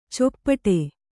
♪ coppaṭe